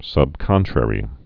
(sŭb-kŏntrĕrē)